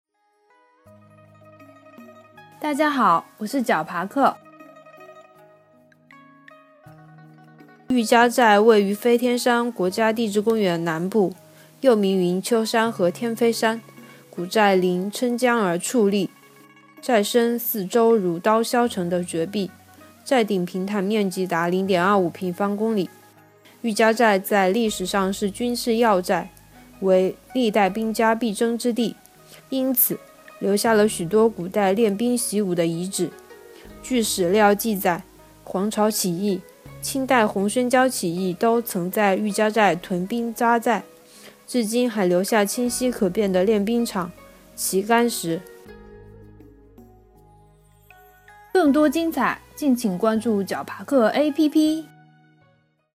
喻家寨----- fin 解说词: 喻家寨位于飞天山国家地质公园南部，又名云秋山和天飞山，古寨临郴江而矗立，寨身四周如刀削成的绝壁，寨顶平坦面积达0.25平方公里。